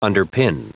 Prononciation du mot underpin en anglais (fichier audio)
Prononciation du mot : underpin